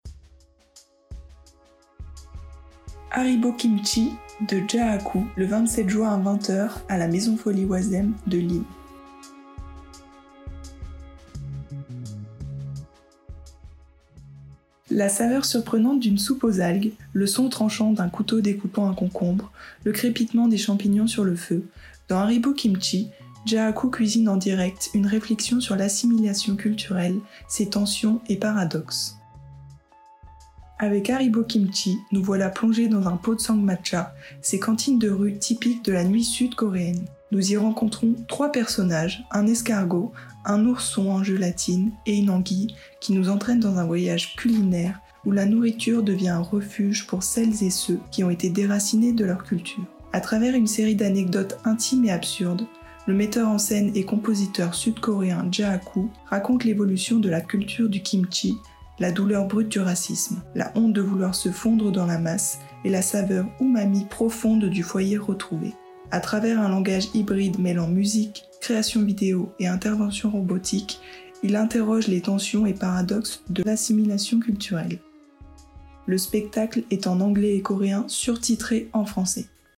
Version audio de la description du spectacle :